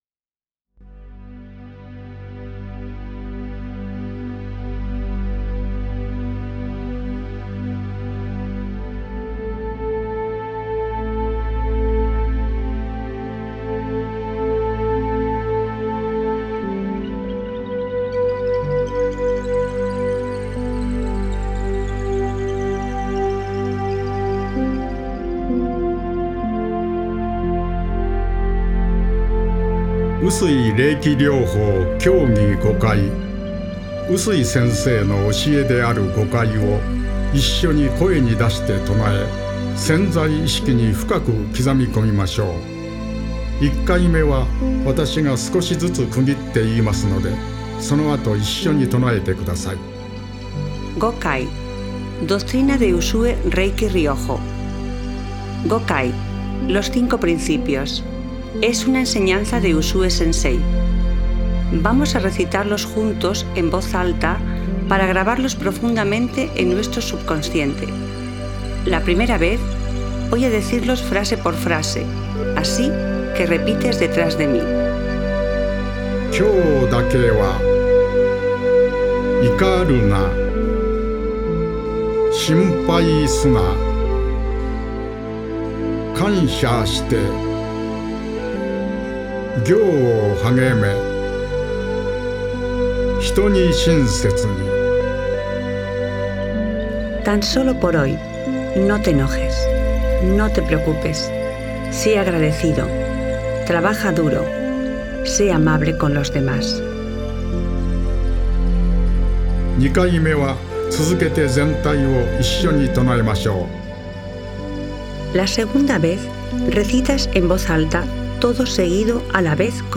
5 principios recitados por el